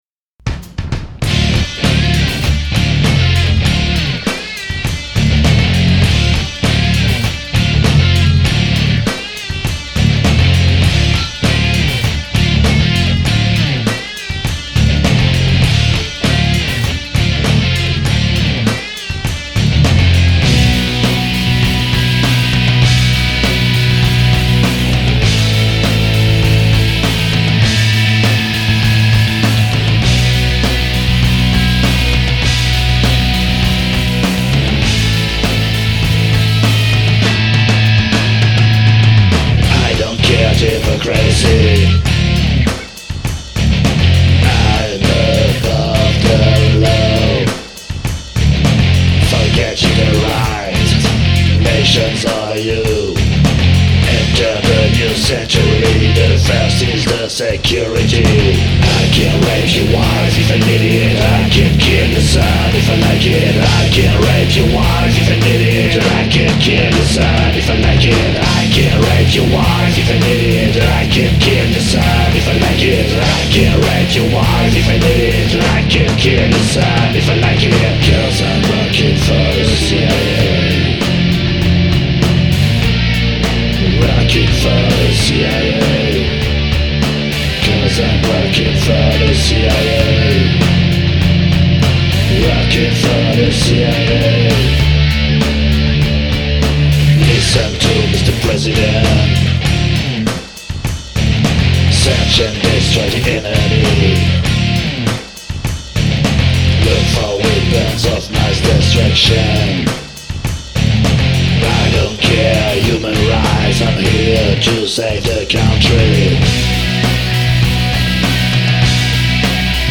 C'est toujours du lourd évidemment